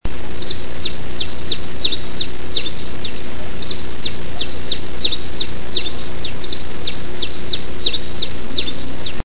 Esses dias me chamou a atenção um casal de canários-da-terra que nidificaram na avenida Iperoig em frente à Câmara Municipal, perto do meio-dia cantam sem parar.
canários-da-terra não convivem com o homem, em Ubatuba estão lá, na avenida principal.
Todos sons do centro da cidade de Ubatuba.